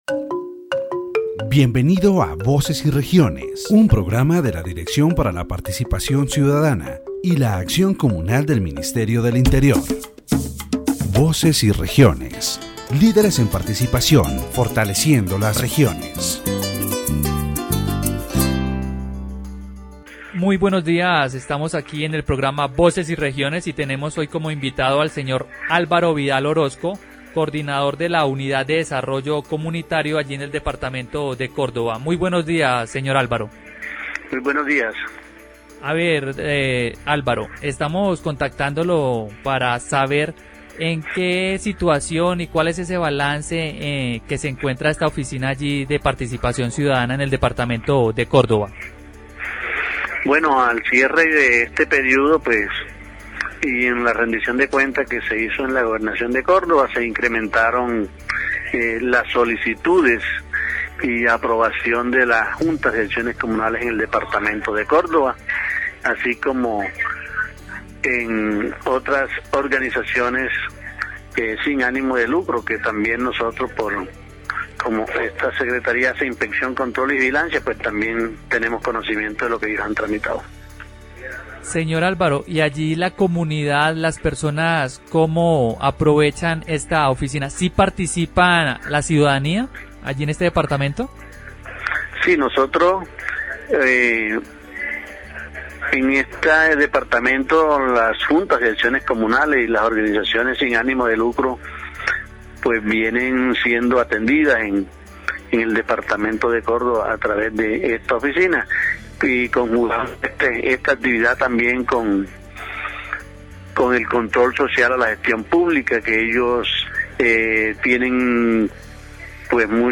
In this section of Voces y Regiones, the interviewee discusses the role of the Office of Citizen Participation in accountability and the organization of Community Action Boards.